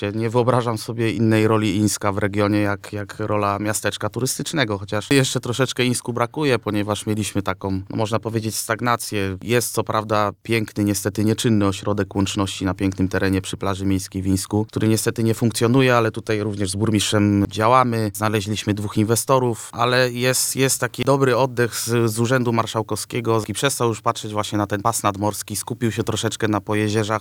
Ińsko ma potencjał do konkurowania nawet z nadmorskimi miejscowościami, ale trzeba zadbać o dalszą rozbudowę udogodnień dla turystów – przyznaje zastępca burmistrza Michał Kupczyński.